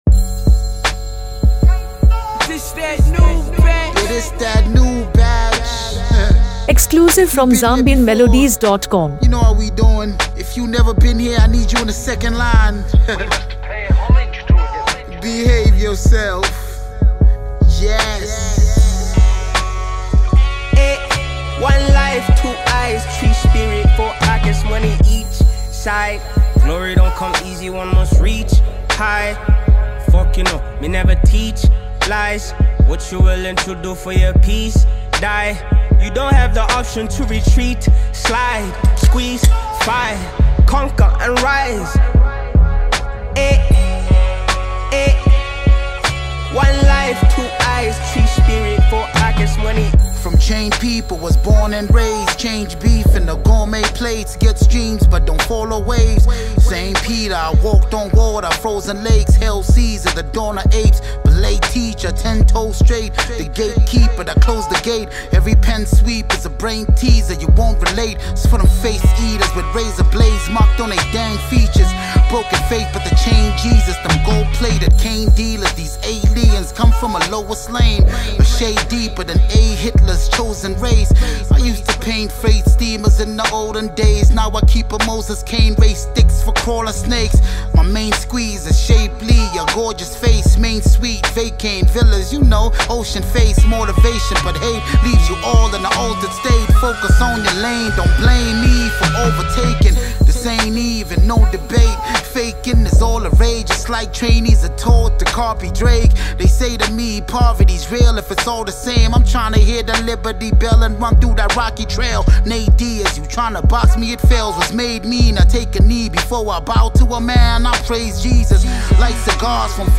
A Powerful South African Hip-Hop Collaboration